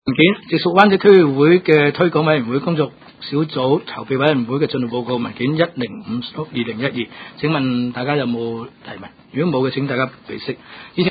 区议会大会的录音记录
湾仔区议会第七次会议